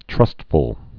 (trŭstfəl)